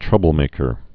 (trŭbəl-mākər)